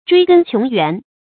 追根窮源 注音： ㄓㄨㄟ ㄍㄣ ㄑㄩㄥˊ ㄧㄨㄢˊ 讀音讀法： 意思解釋： 同「追本溯源」。